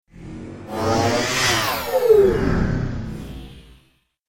دانلود آهنگ دوچرخه 16 از افکت صوتی حمل و نقل
دانلود صدای دوچرخه 16 از ساعد نیوز با لینک مستقیم و کیفیت بالا
جلوه های صوتی